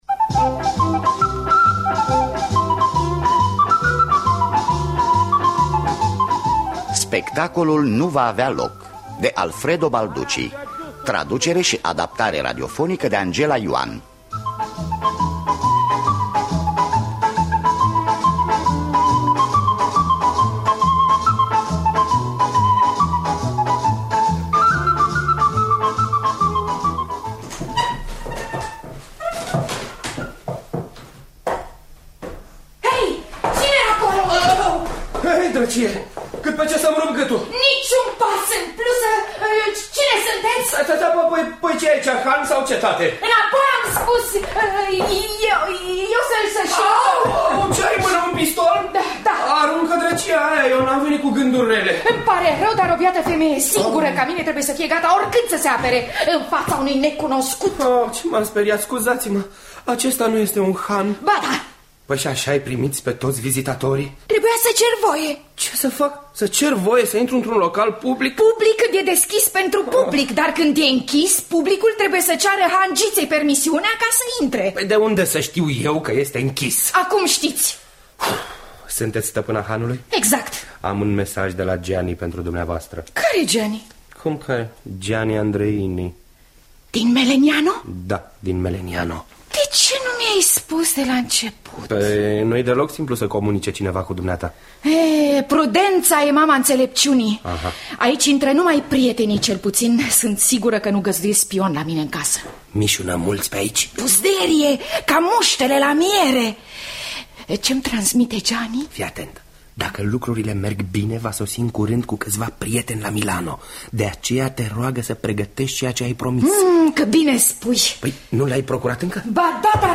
Spectacolul nu va avea loc de Alfredo Balducci – Teatru Radiofonic Online